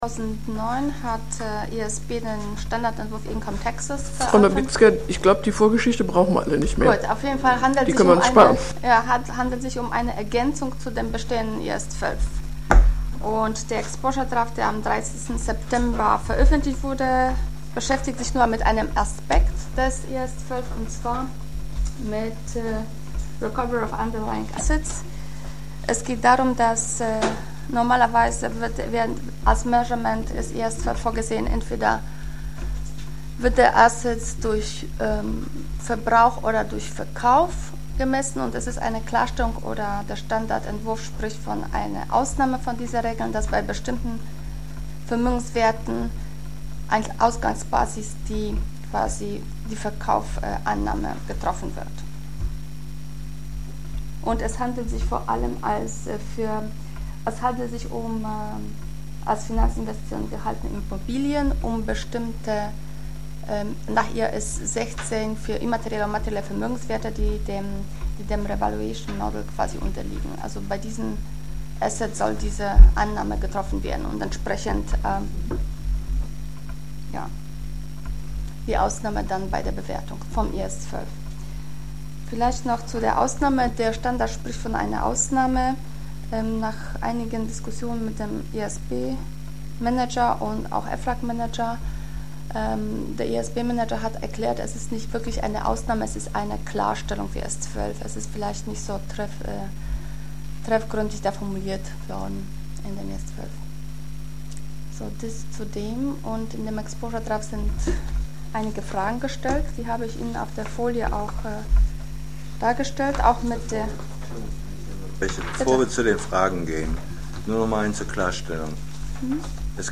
149. DSR-Sitzung • DRSC Website
Der DSR setzt seine in der vorangegangenen Sitzung begonnene Diskussion der vom IASB im Rahmen des ED/2010/9 Leases unterbreiteten Vorschläge zur Neugestaltung der Leasingbilanzierung fort. Auf Basis von der Arbeitsgruppe „Leases“ vorbereiteter Antworten erörtern die Mitglieder des DSR die ersten vier Fragen des ED.